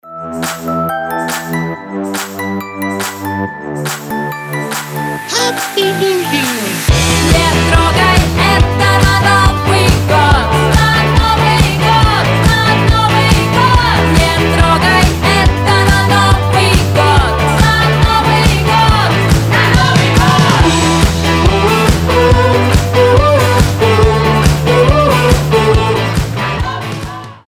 • Качество: 320, Stereo
веселые
смешные
поп-панк